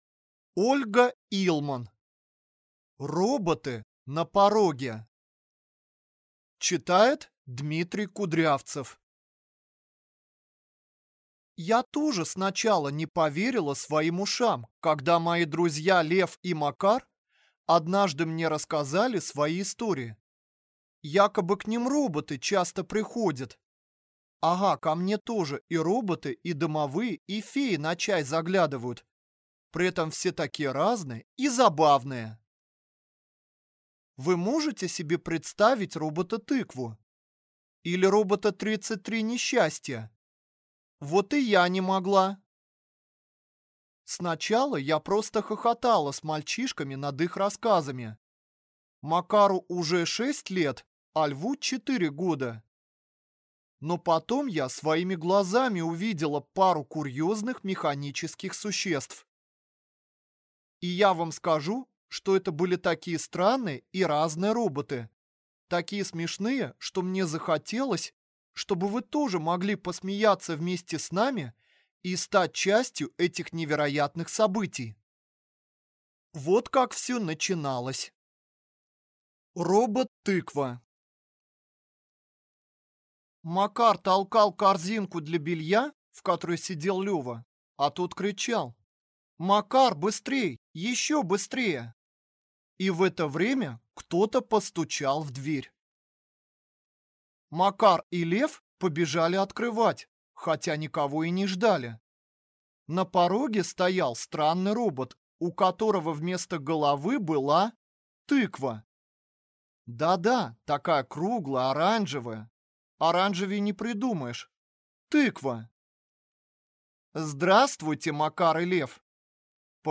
Аудиокнига Роботы на пороге | Библиотека аудиокниг
Прослушать и бесплатно скачать фрагмент аудиокниги